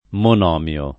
monomio
monomio [ mon 0 m L o ]